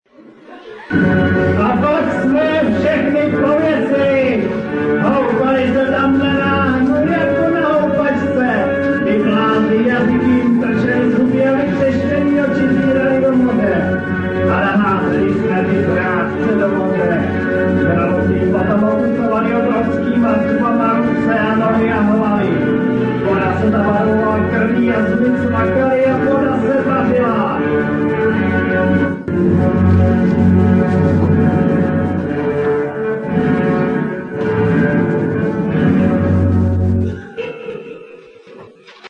Bylo to 27. dubna 2002 v rámci farního dne.